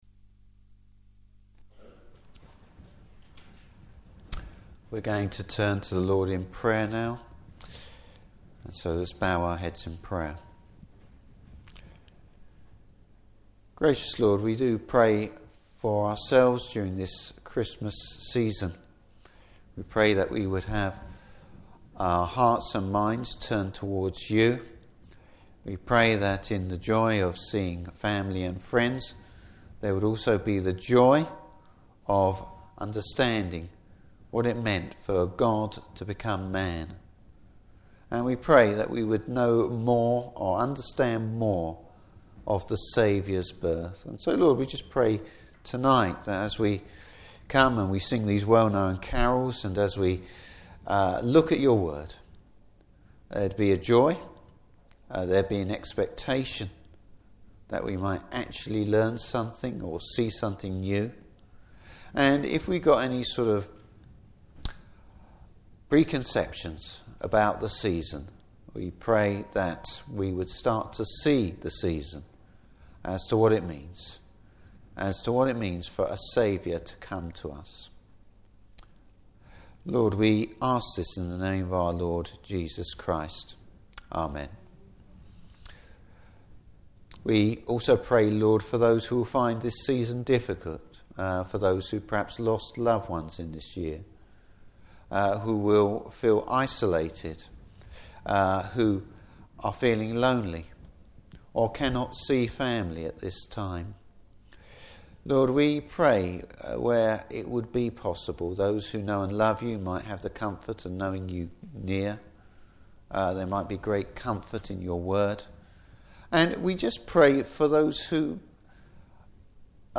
Service Type: Carol Service The faithfulness of God.